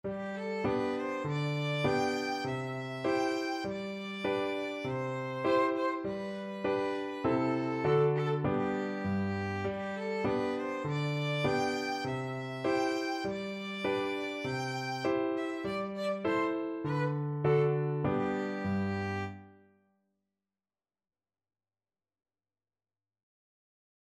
Moderato
G5-G6
Beginners Level: Recommended for Beginners